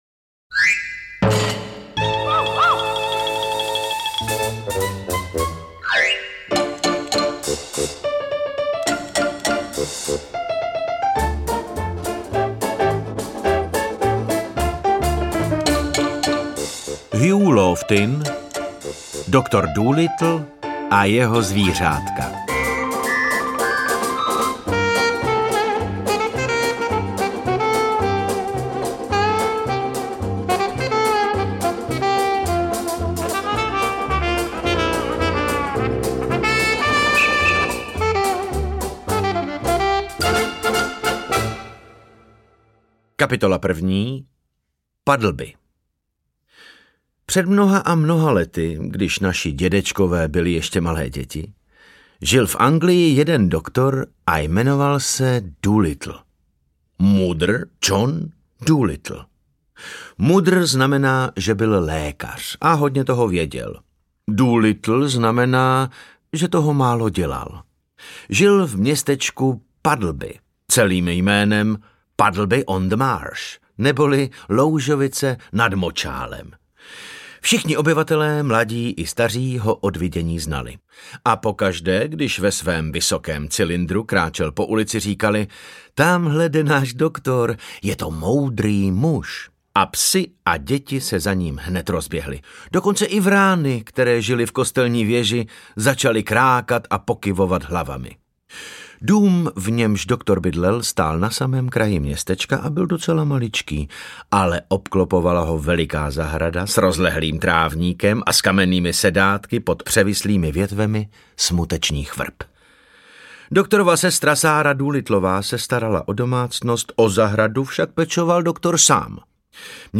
• AudioKniha ke stažení Lofting: Doktor Dolittle a jeho zvířátka
Interpreti:  David Novotný, David Novotný, David Novotný